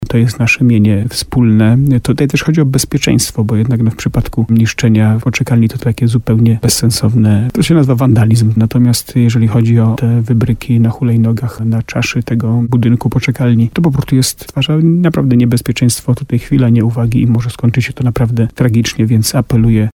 Burmistrz Jacek Lelek w programie Słowo za Słowo na antenie RDN Nowy Sącz apelował do osób, które dokonują takich rzeczy, ale też do ich opiekunów.